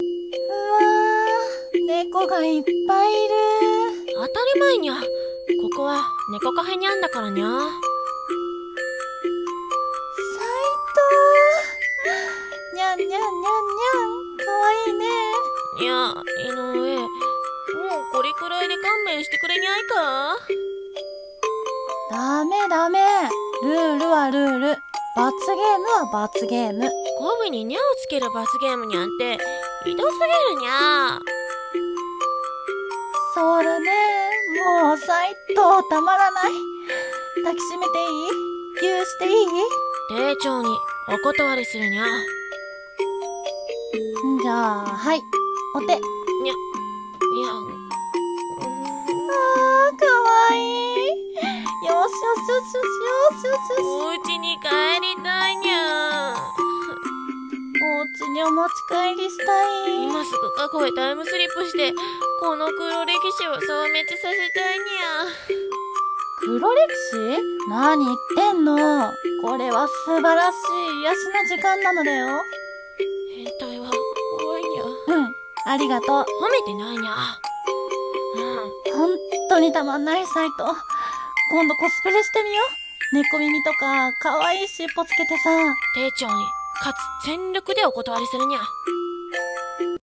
ギャグ台本『猫カフェに行くにゃ！」掛け合い 声劇